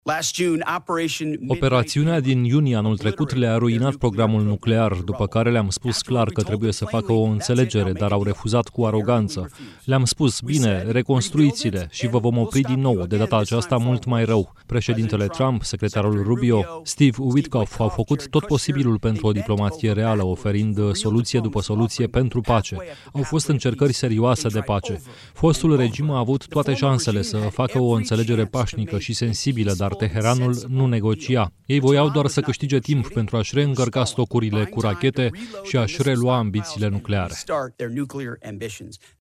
În urmă cu câteva minute, într-o conferință de presă, șeful Pentagonului, Pete Hegseth, a explicat care este motivul pentru care Statele Unite au declanșat această operațiune.